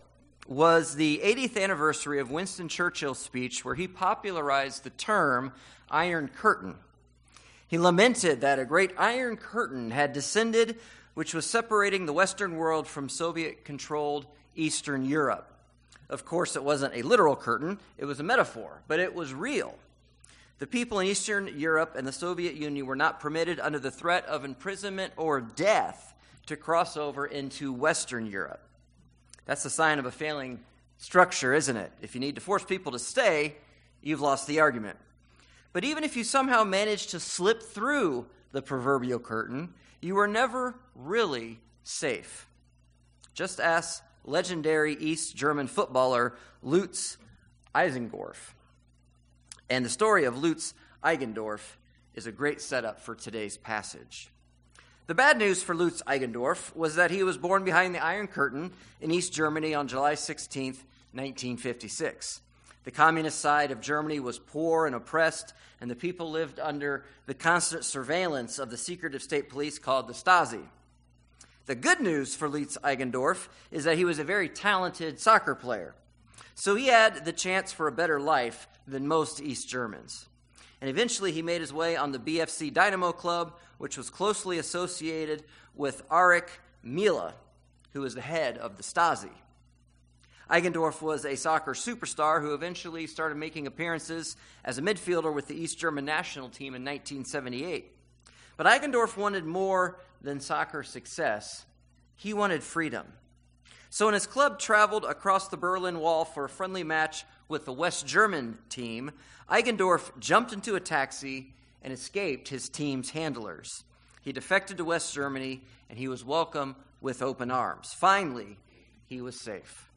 Good Friday Service